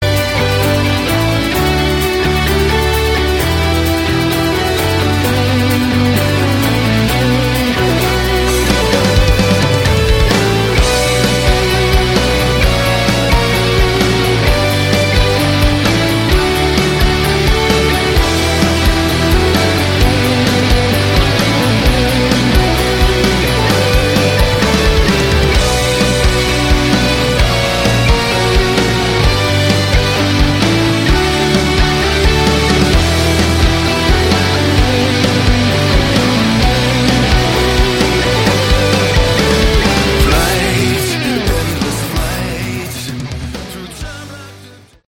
Category: AOR
vocals, guitars, backing vocals
keyboards and synthesisers
bass guitars
additional drums and percussion